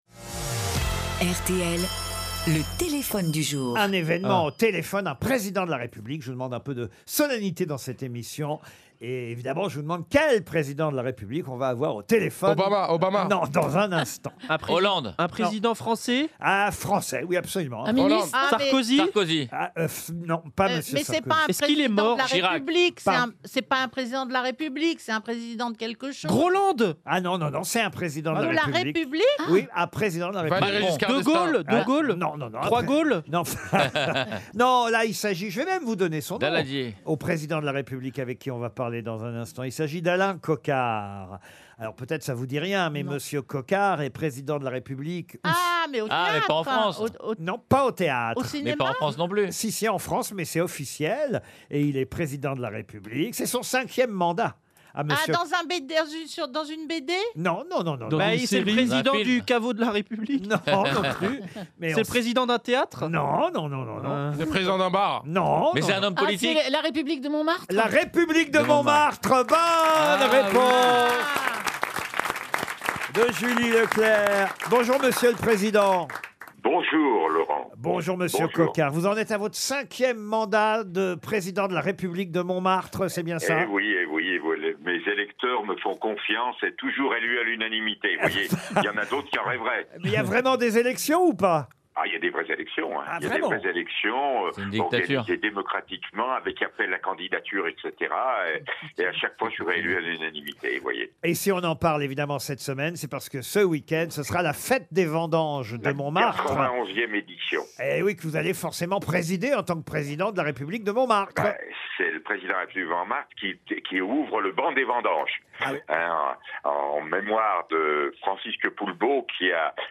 Laurent Ruquier a accueilli